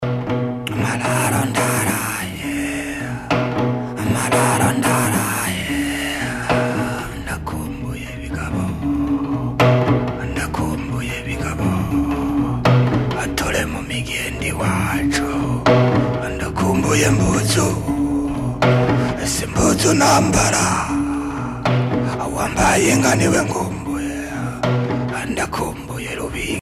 Chant de louange destiné à un bienfaiteur
chanté-chuchoté
Pièce musicale éditée